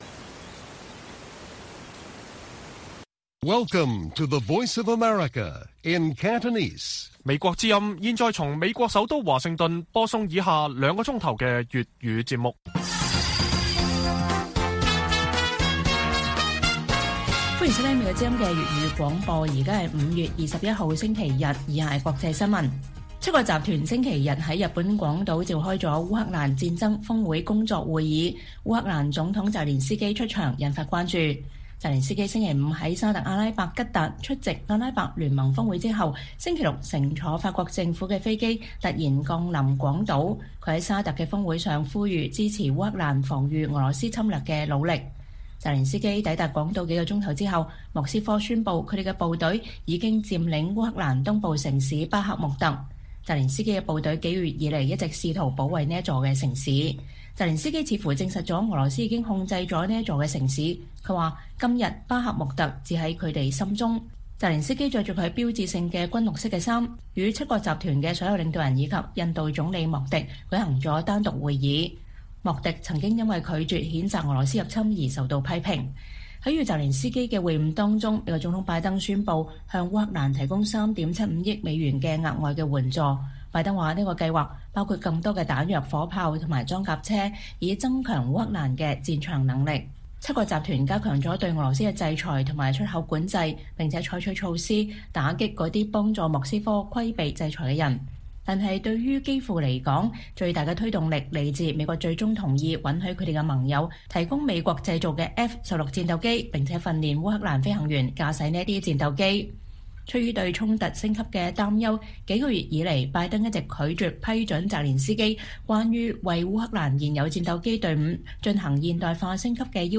粵語新聞 晚上9-10點: 澤連斯基在G7會議講話 各國領導人增加對俄施壓